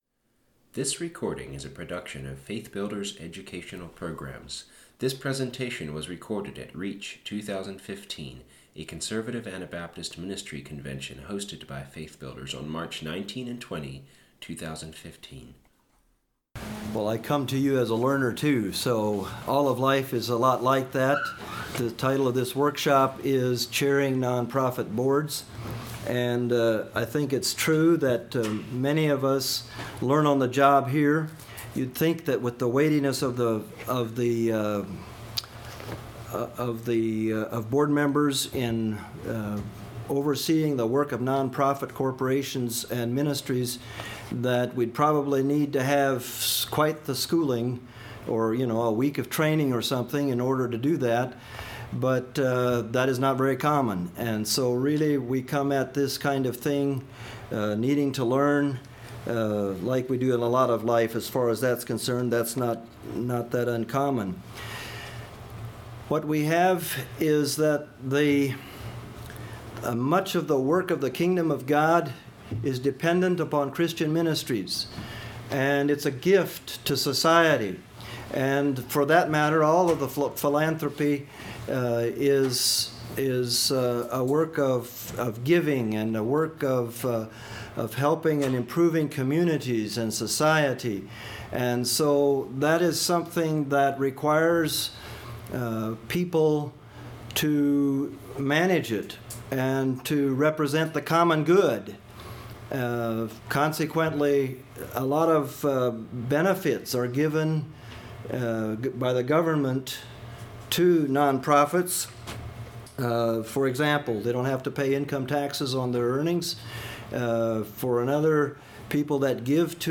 Home » Lectures » Chairing Nonprofit Boards